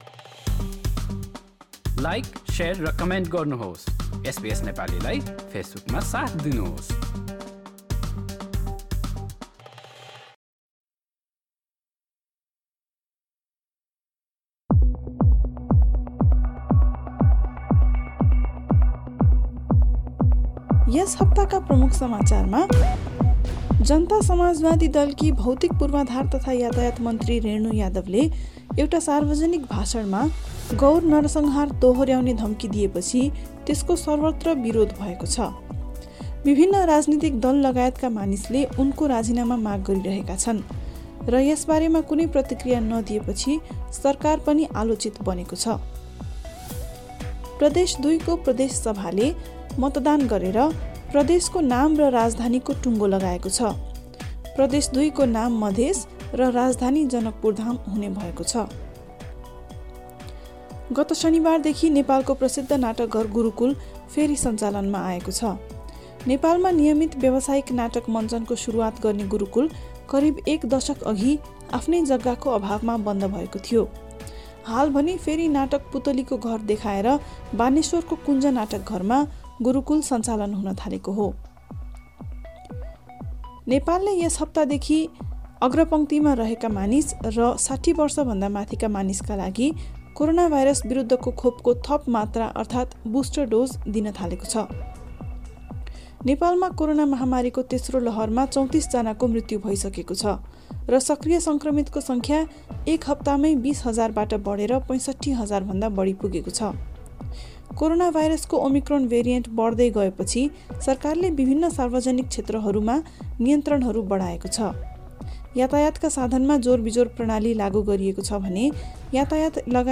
विशेष कुराकानीका साथ नेपालका पछिल्ला सात दिनका प्रमुख समाचारहरू सुन्नुहोस्।